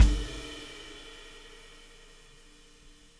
Weathered Beat Fill 01.wav